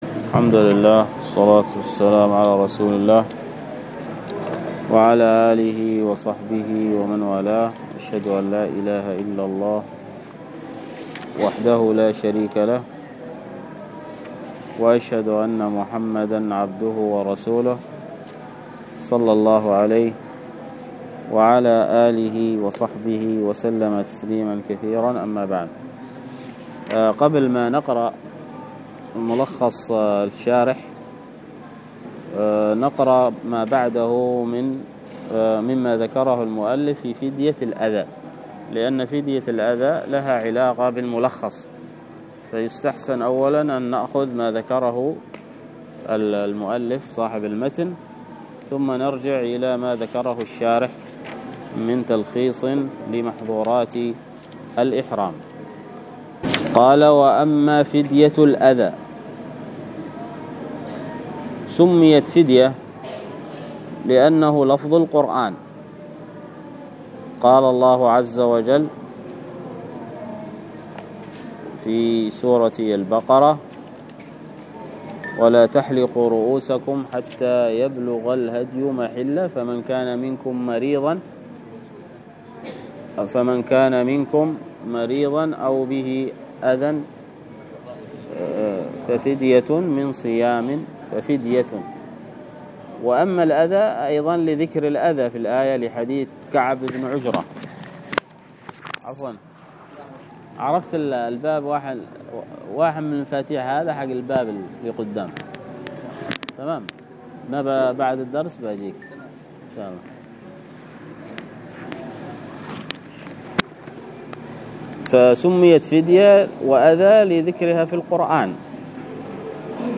الدرس في شرح فتح المجيد 90، ألقاها